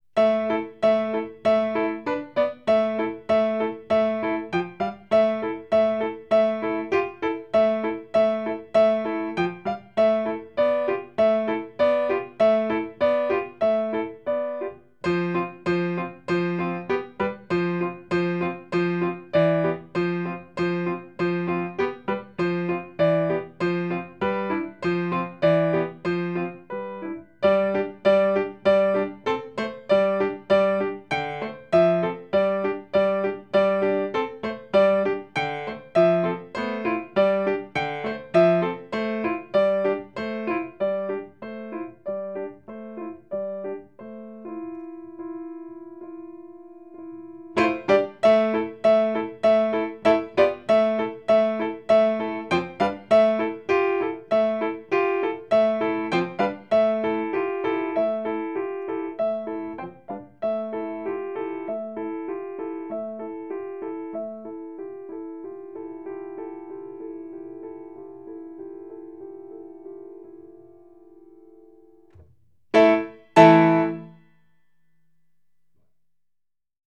Solos piano